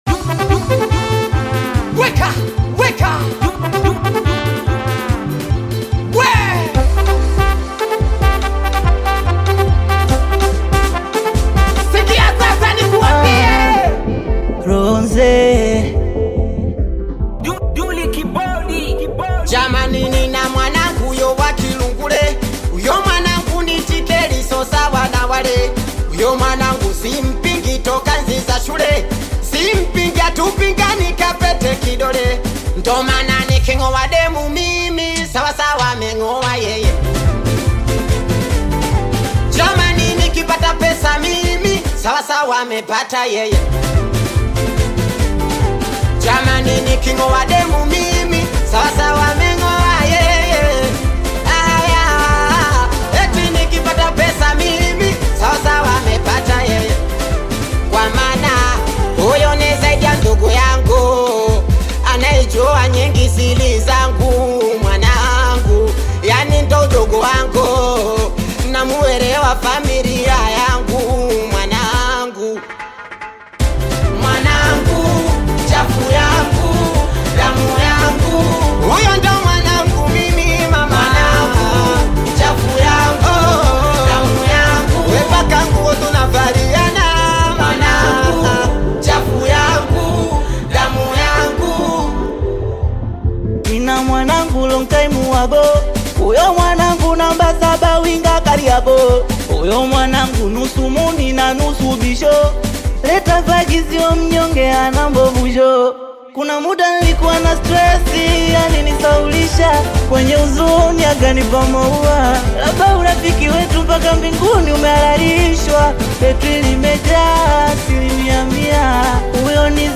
Singeli music track